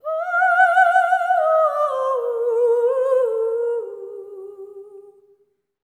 LEGATO 03 -R.wav